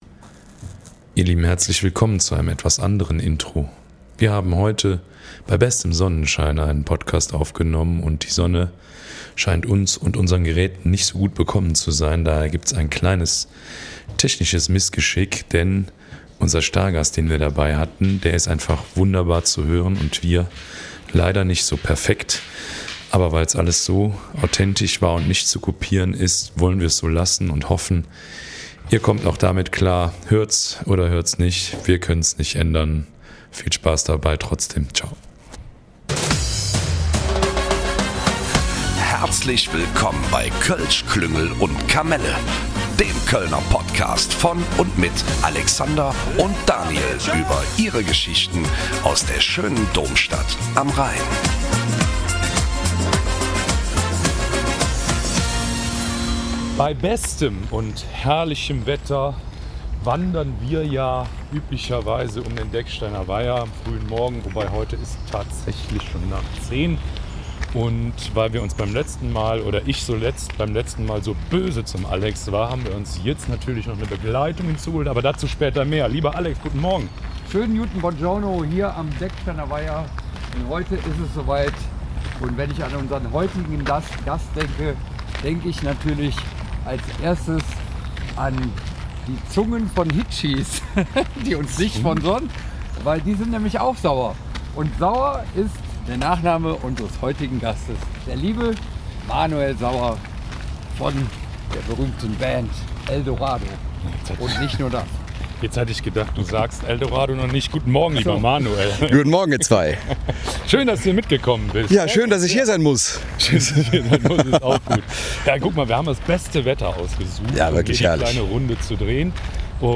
By the way; müssen wir uns in der aktuellen Folge für die ungleiche Tonlautstärke entschuldigen.